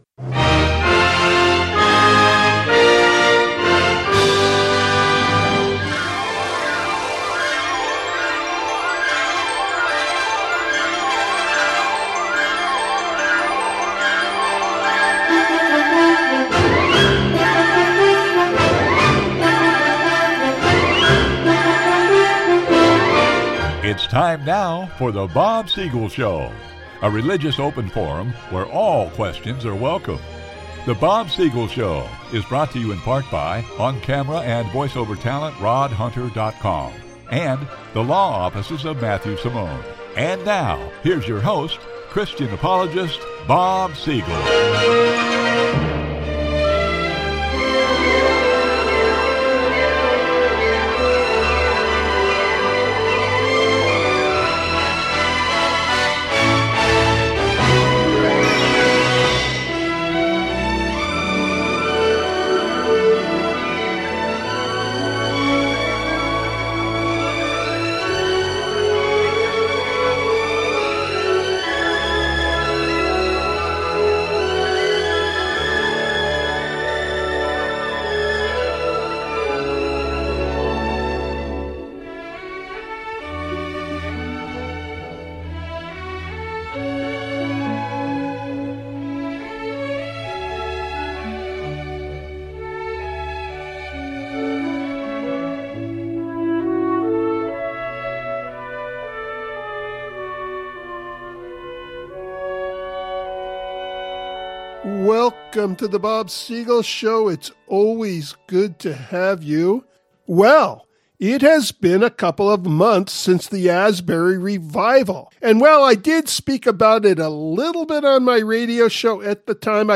This monologue is found at the top of the program. Other topics of discussion follow.